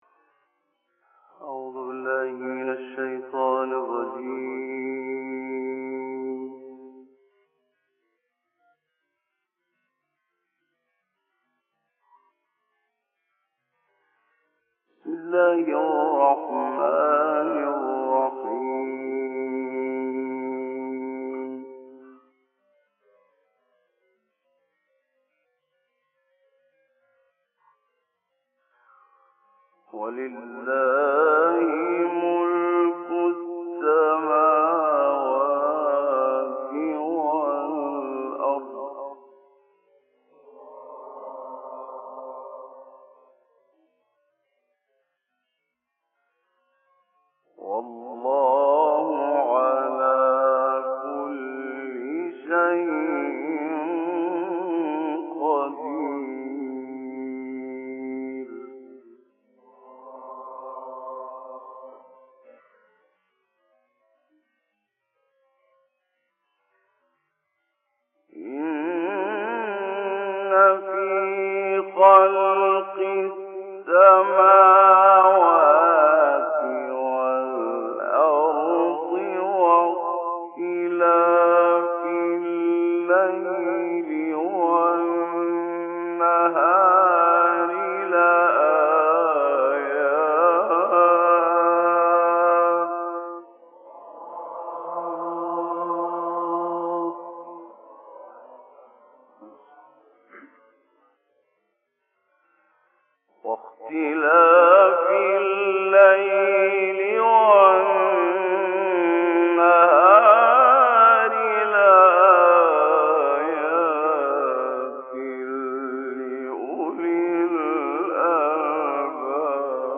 سوره : آل عمران آیه : 189*195 استاد : محمد لیثی مقام : مرکب خوانی(بیات * حجاز) قبلی بعدی